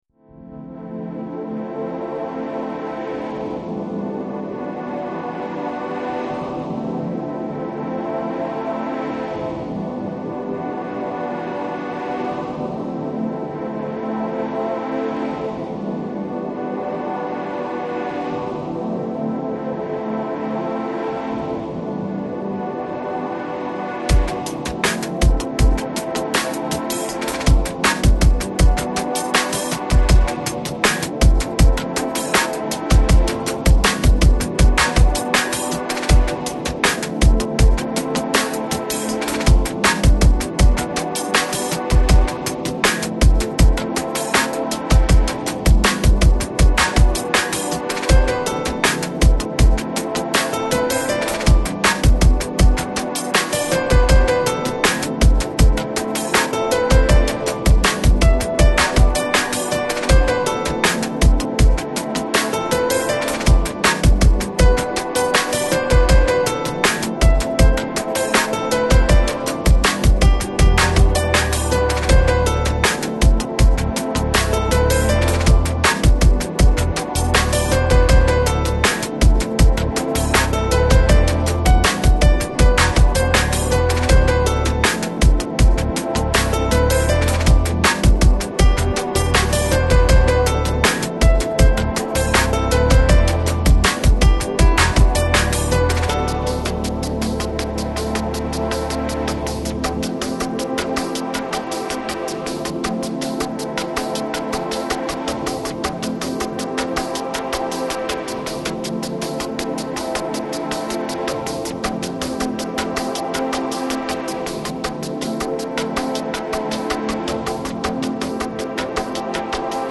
Жанр: Electronic, Lounge, Chill Out, Downtempo